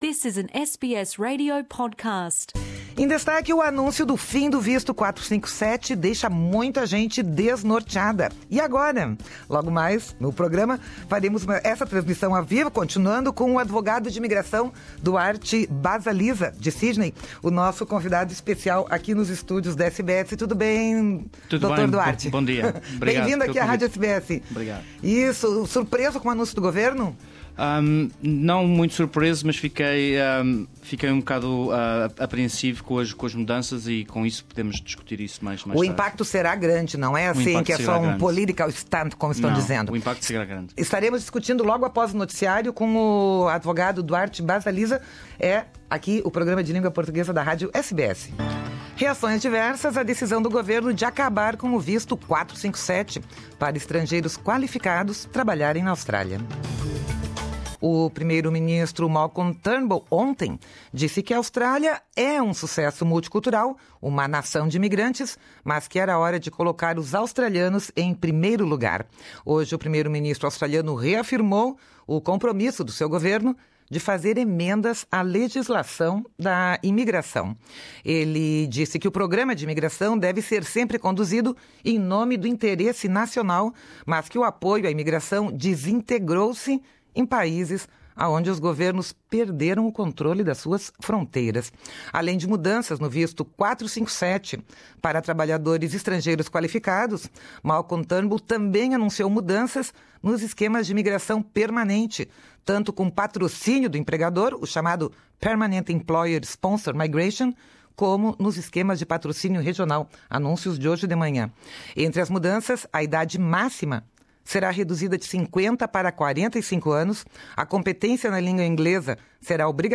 Anúncio do fim - ou "metamorfose" - do visto 457 deixou muita gente desnorteada. Acompanhe aqui o podcast da reportagem e a entrevista